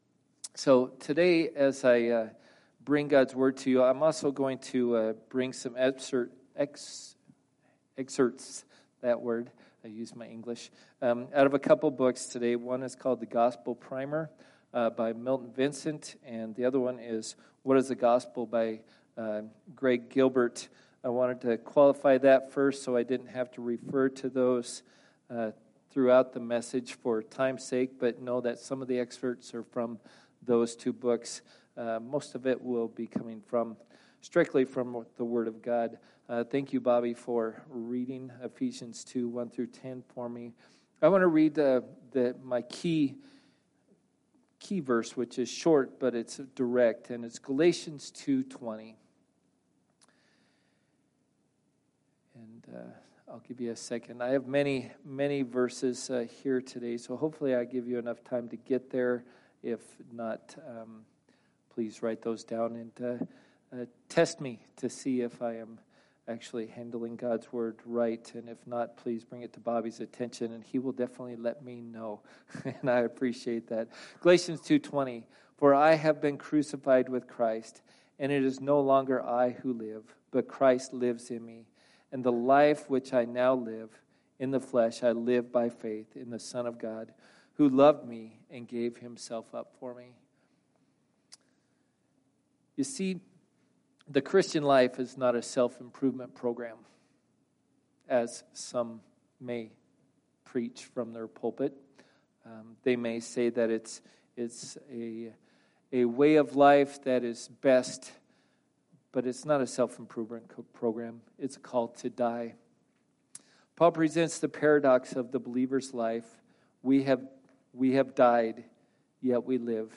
From Series: "Guest Preacher"